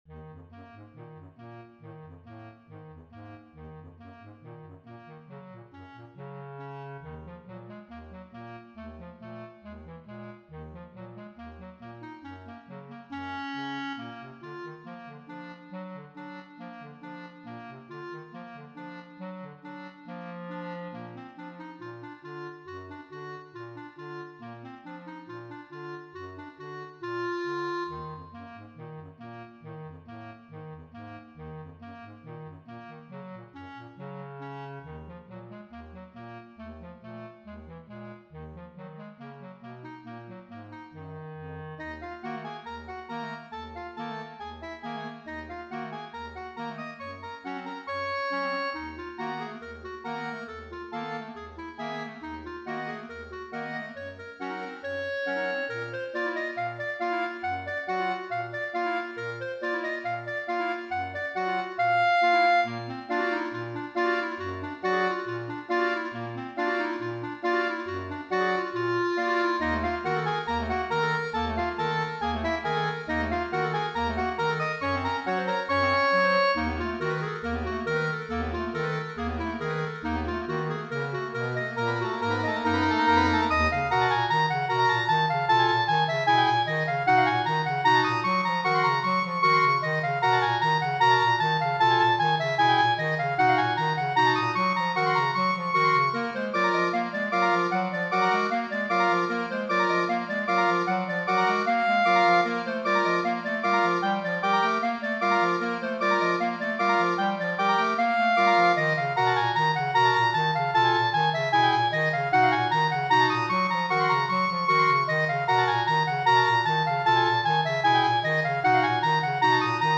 Voicing: Clarinet Quintet